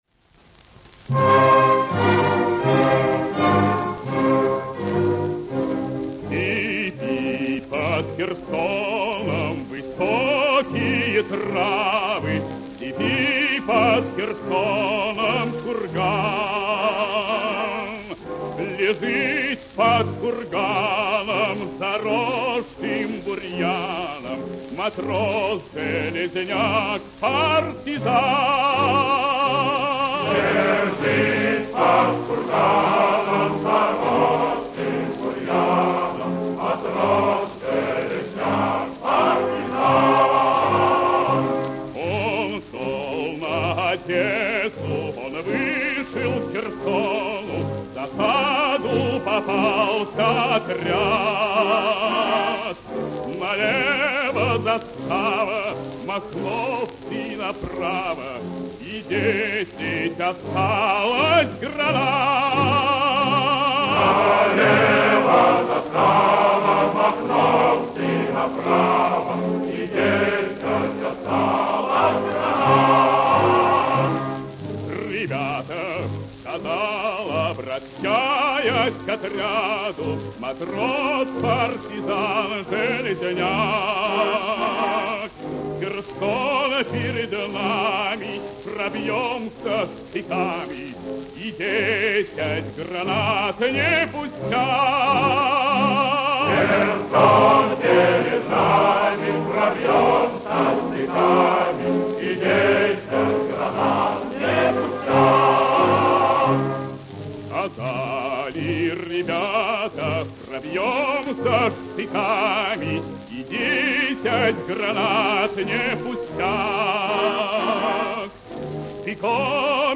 - рус. сов. певец (баритон), нар. арт.